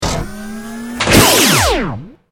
battlesuit_medlaser.ogg